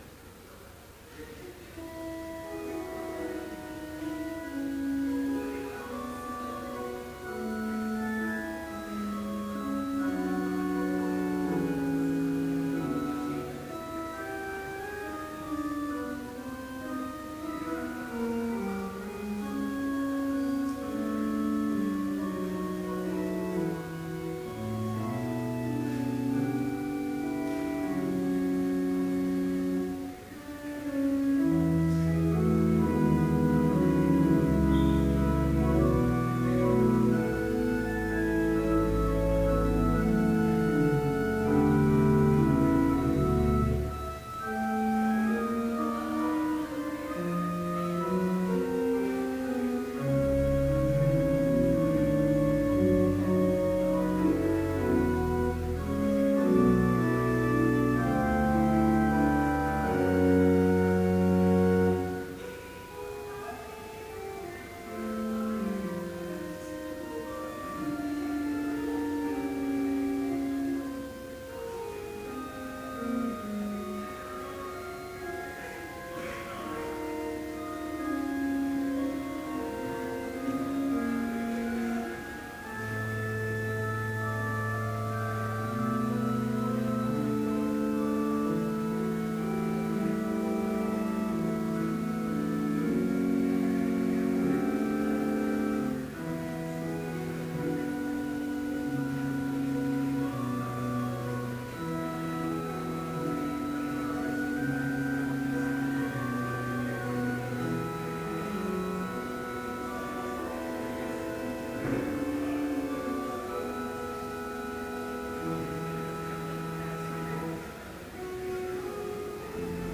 Complete service audio for Chapel - April 15, 2013